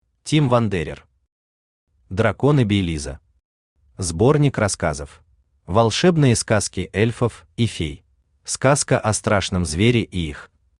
Аудиокнига Драконы Бейлиза. Сборник рассказов | Библиотека аудиокниг
Сборник рассказов Автор Тим Вандерер Читает аудиокнигу Авточтец ЛитРес.